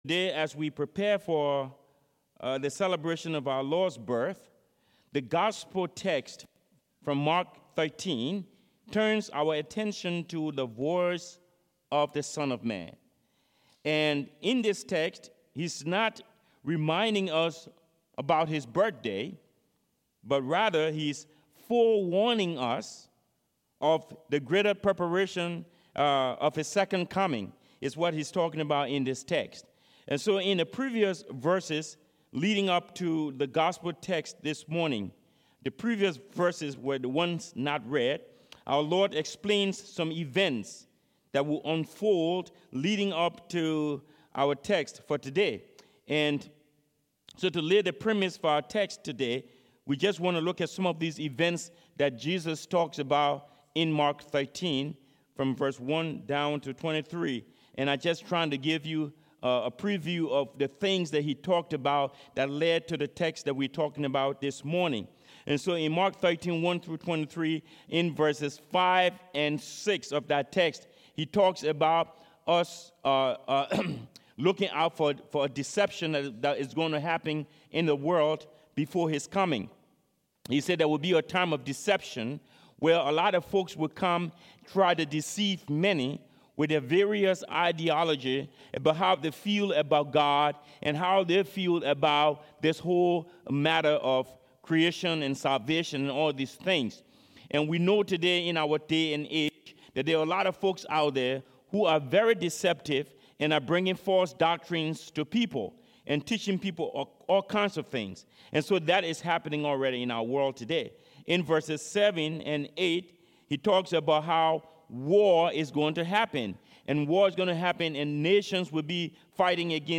Service Type: Sermons